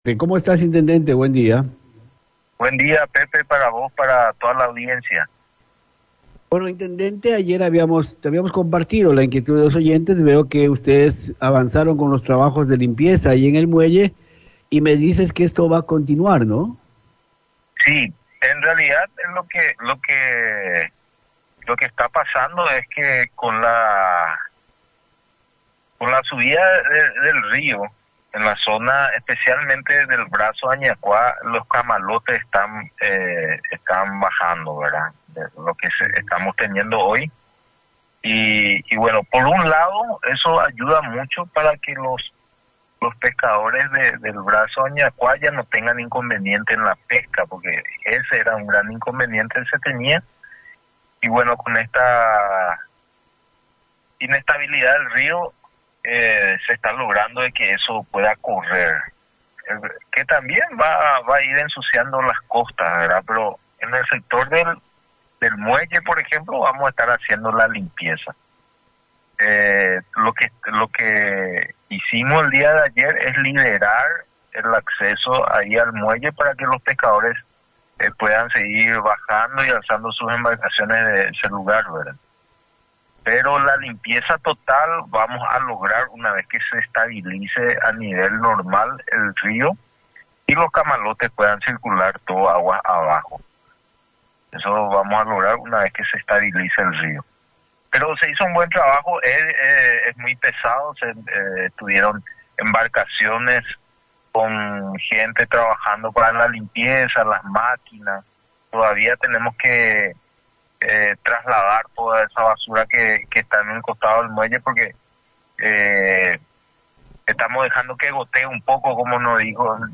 El Intendente de Ayolas, Abg. Carlos Duarte informó de los trabajos que está realizando la Municipalidad local para liberar el acceso a los pescadores en el sector del muelle, indicando además detalles de la reunión mantenida con el Vicecanciller, Embajador Raúl Silvero Silvagni, con el fin de coordinar las acciones para la rehabilitación total del paso fronterizo entre las ciudades de Ayolas e Ituzaingó.